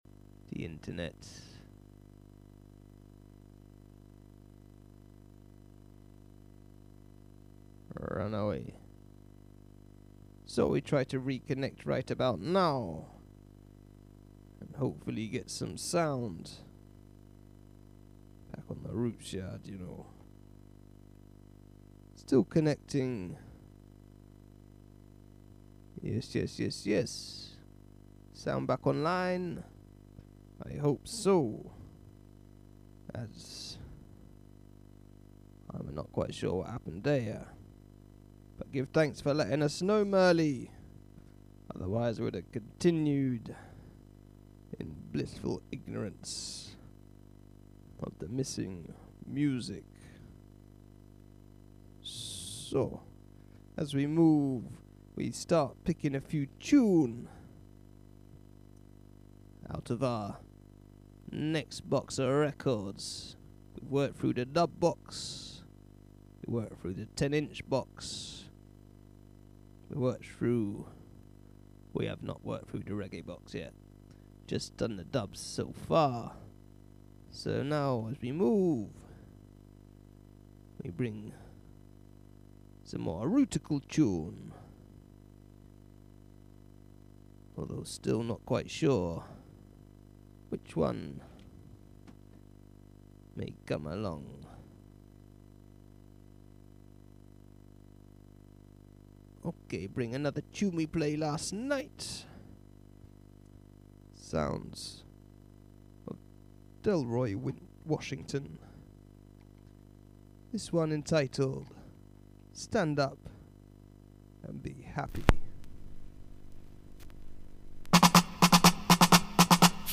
Vocal Vibration
Drum Jam